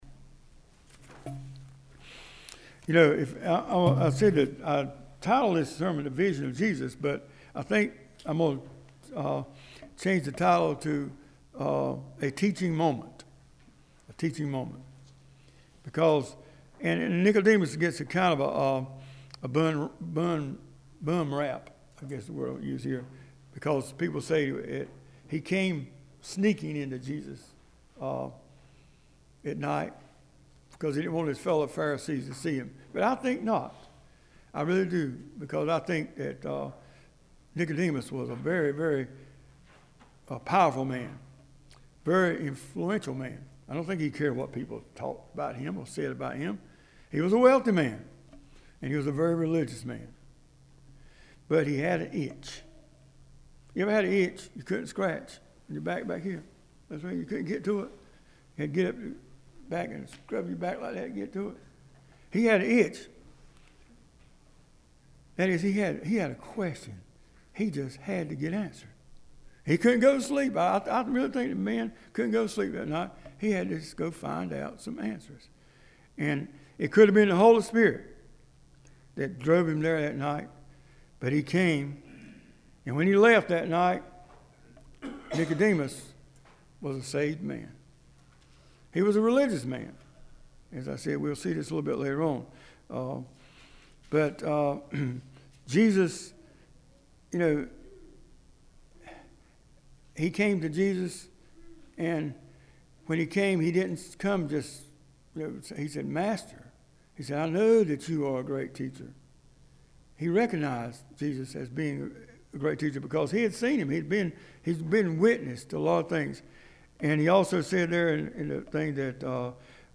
This weeks scripture and sermon:
3-12-sermon.mp3